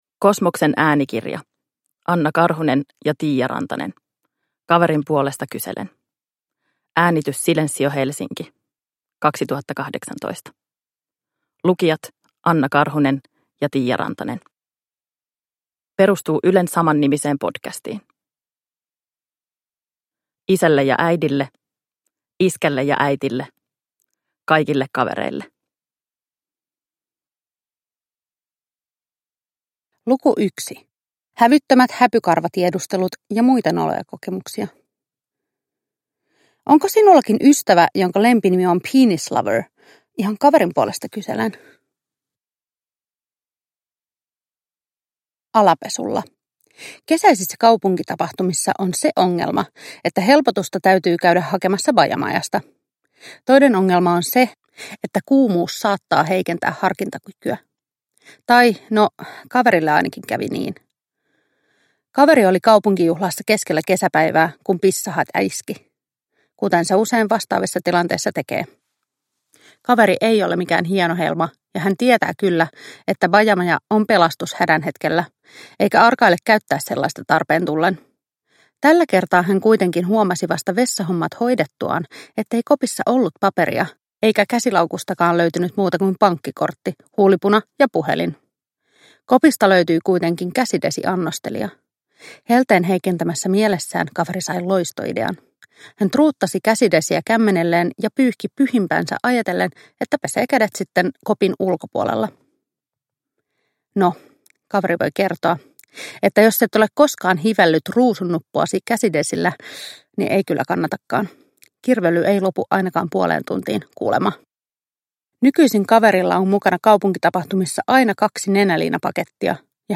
Kaverin puolesta kyselen – Ljudbok